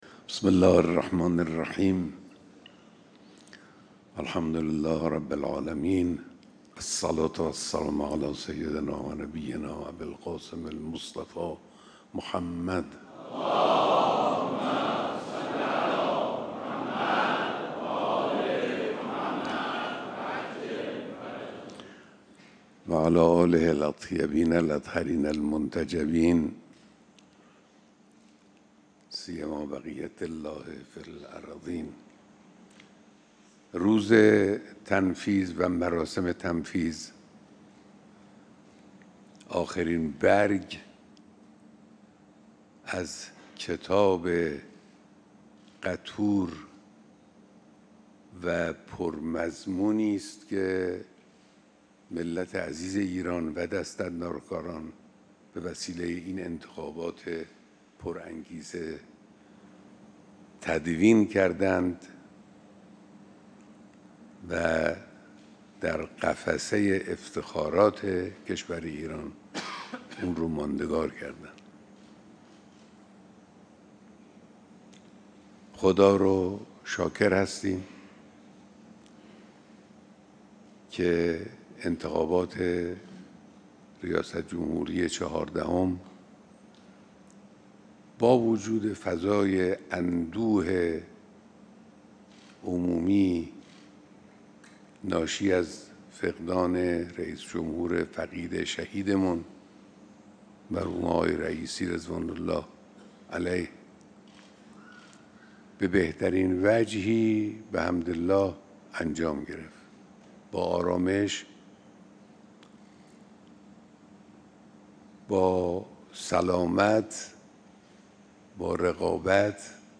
بیانات در مراسم تنفیذ حکم ریاست جمهوری آقای دکتر مسعود پزشکیان